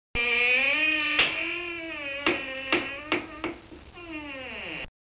squeeky.mp3